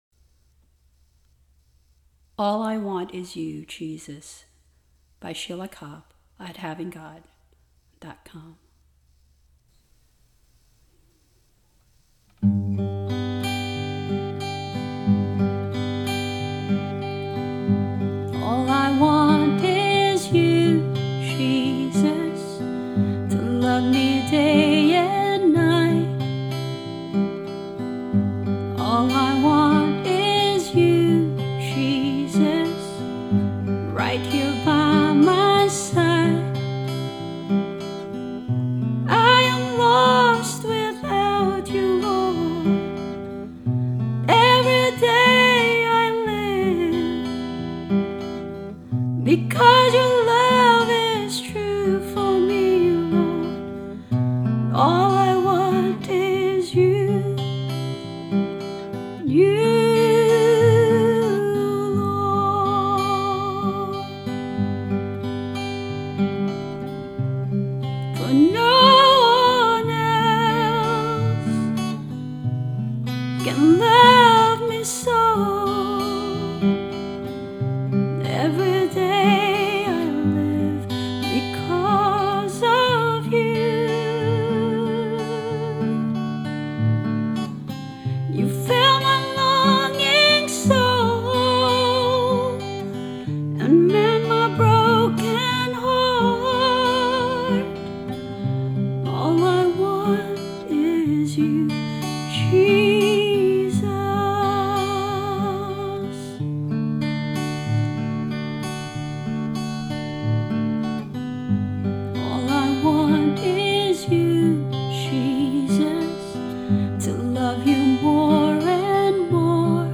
Voice and guitar